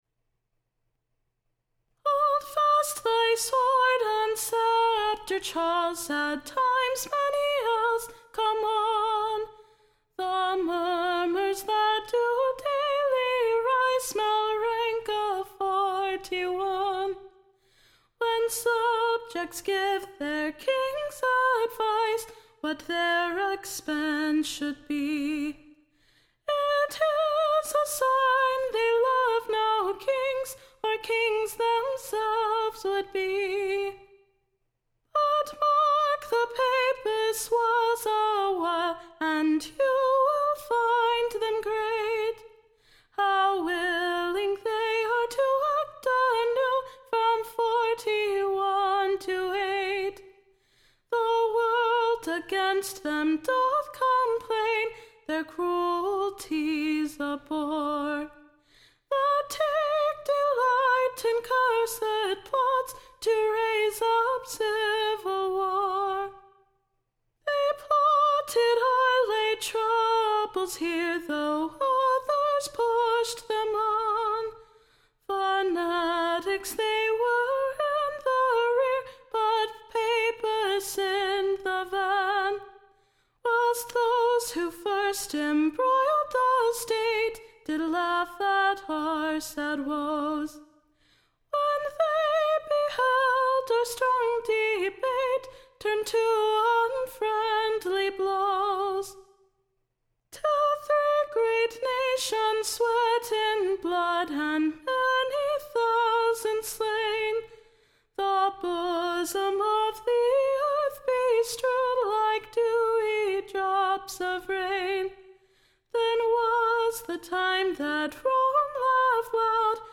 Recording Information Ballad Title Loyalty unfeigned, Or, / The true Protestants Admonition. / Being a Pleasant New SONG.